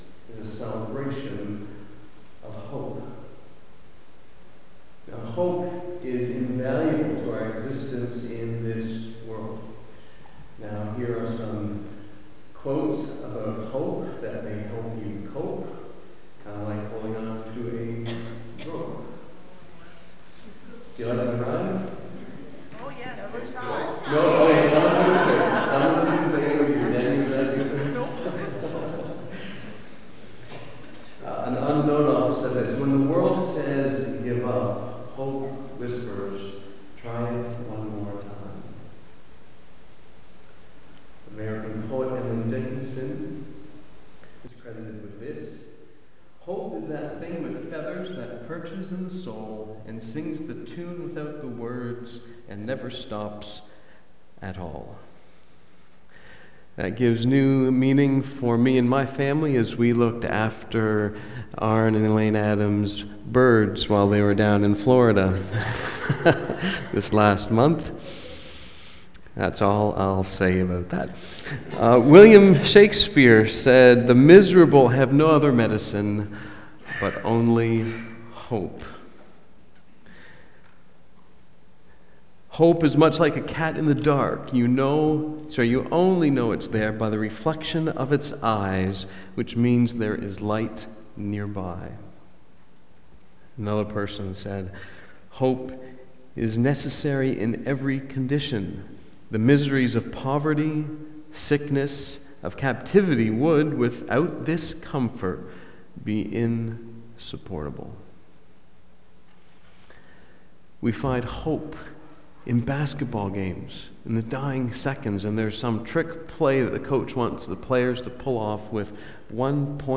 2013 The Promise of a Saviour BACK TO SERMON LIST Preacher